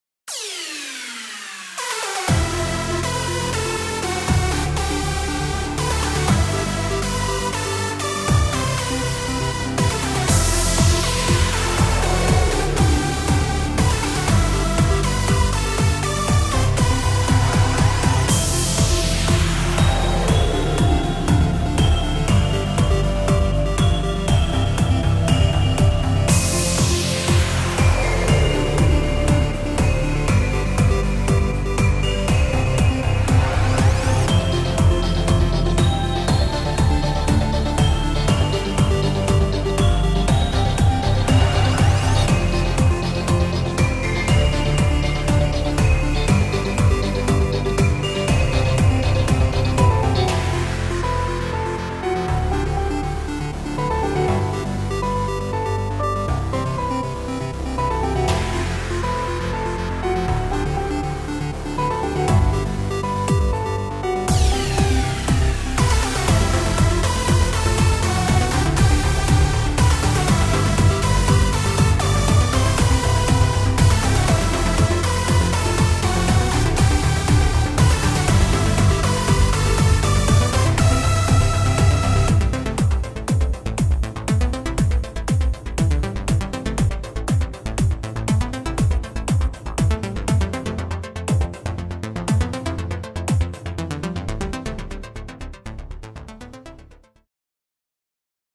フリーBGM 戦闘曲
どこか懐かしくも、未来的な都市の夜景を思わせるデジタル・エモ。
• テンポ：約150BPM前後で疾走感あり
• ドラム：エレクトロニックで硬質なビート（ややブレイクビーツ風）
• シンセサウンド：中域に厚みを持たせたリードとサイドチェインを効かせたパッドが主役
• コード進行：エモーショナルなマイナーコード中心でサビ的な部分では転調的変化も